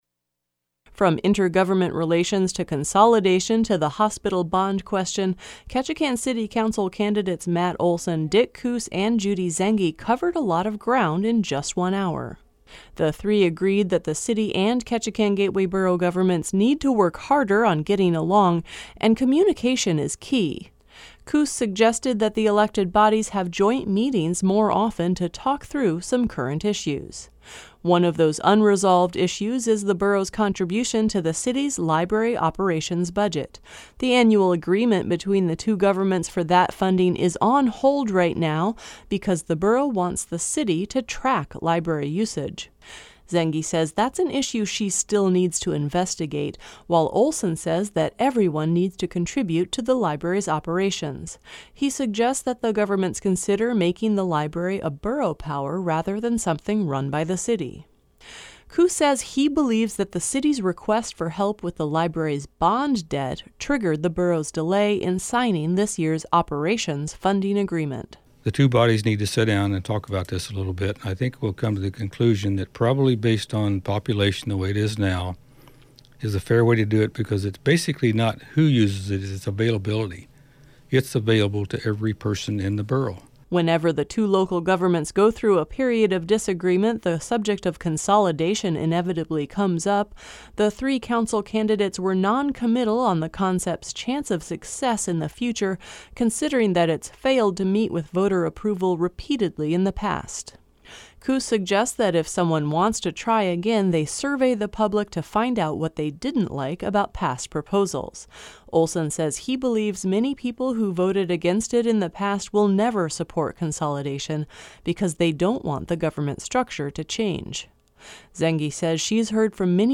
Candidates seeking a seat on the Ketchikan City Council sat down with KRBD on Thursday to talk on the air about current issues.